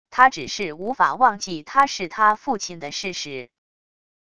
他只是无法忘记他是他父亲的事实wav音频生成系统WAV Audio Player